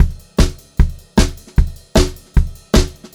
152SPCYMB1-L.wav